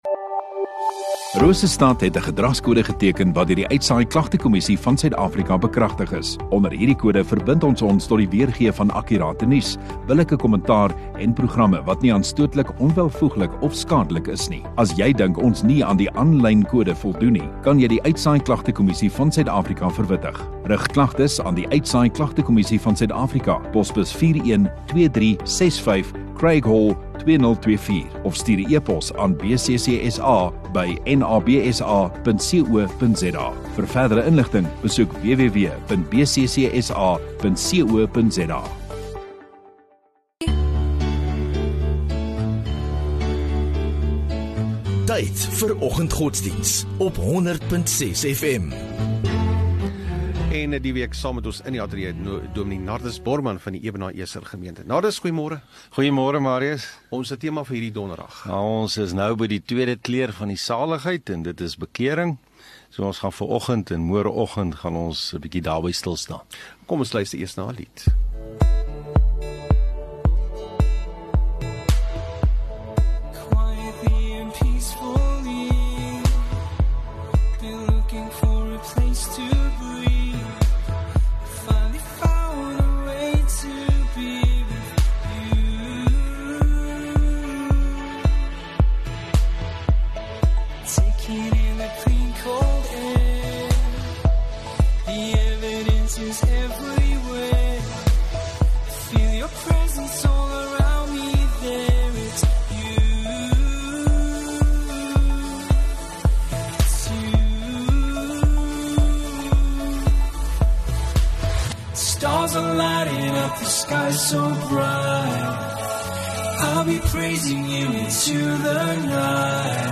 17 Oct Donderdag Oggenddiens